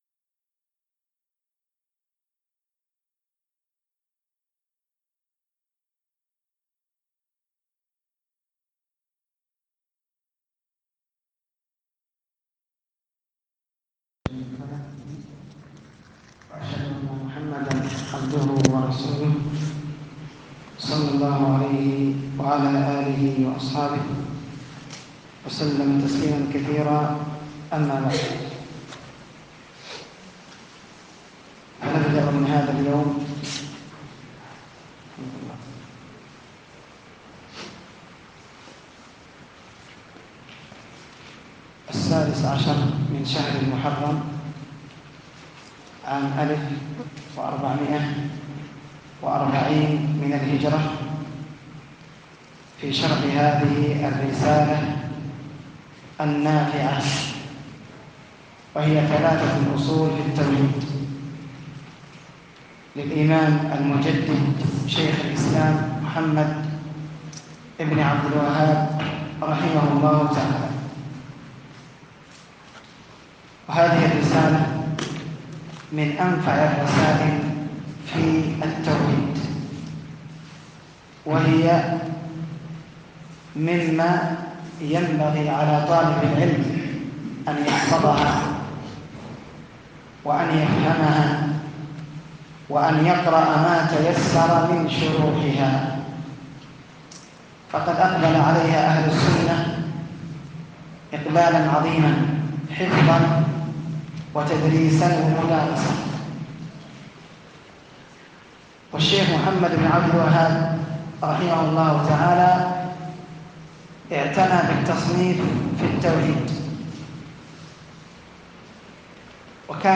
COURS-1-LES-3-FONDEMENTS.mp3